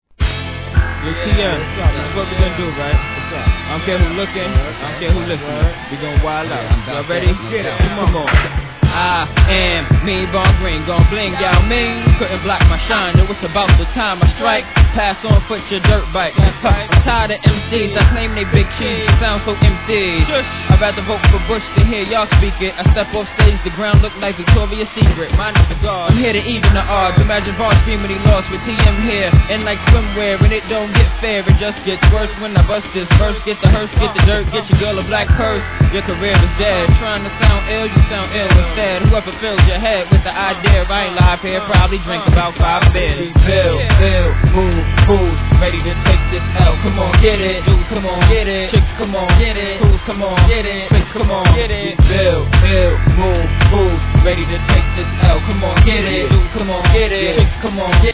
HIP HOP/R&B
疾走感溢れるBEATでのMICリレー!